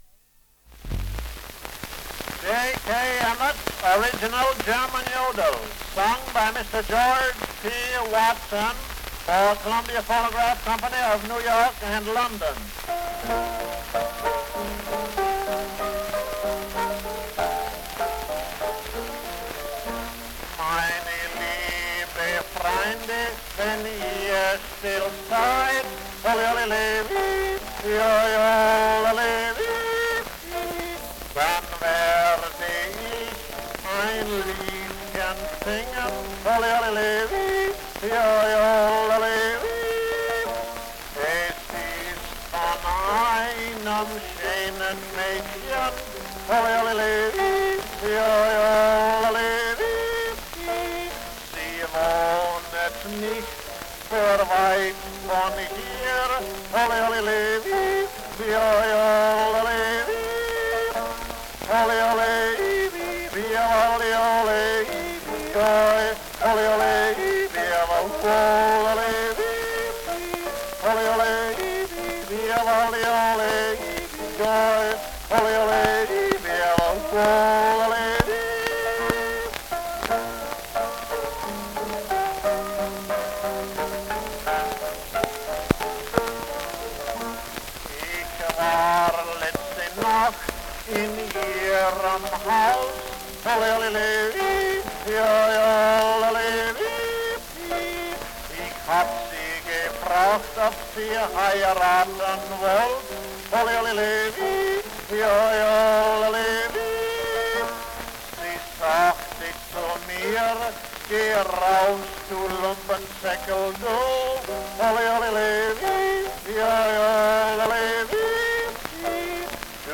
The original German yodel
Ljudinspelningar från omkring 1900